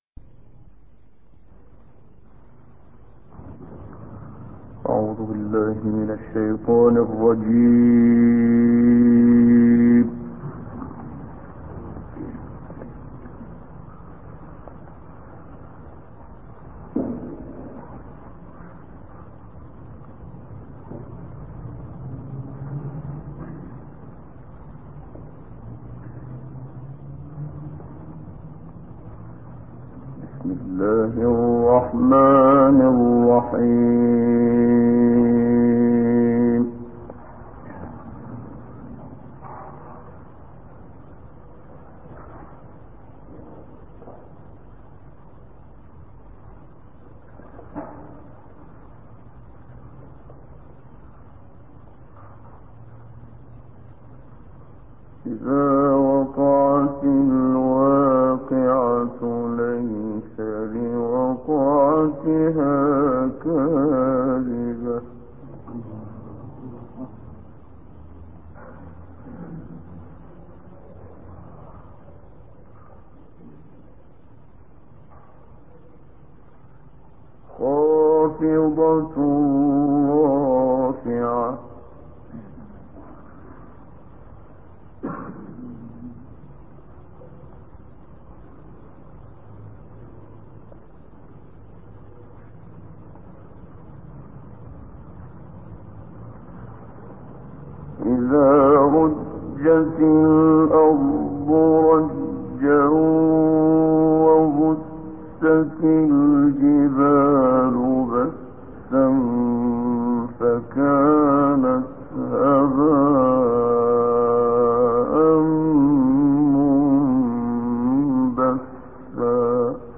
056 الواقعة الحديد 1-5 تلاوات نادرة بصوت الشيخ محمد صديق المنشاوي - الشيخ أبو إسحاق الحويني